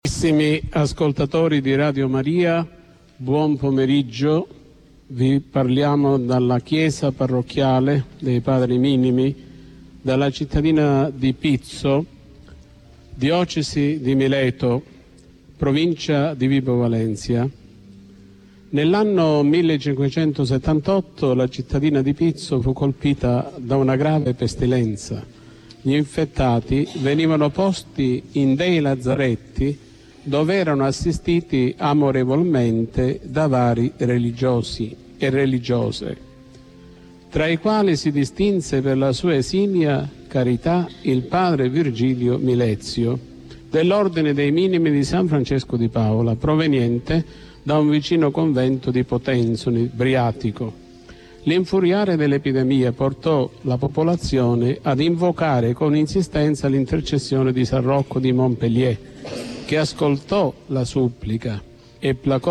Tutto il collegamento di Radio MARIA del 1 Aprile 2009 direttamente da ascoltare in formato mp3
• CORALE DELLA PARROCCHIA DI SAN ROCCO E SAN FRANCESCO DI PAOLA
Omelia Radio Maria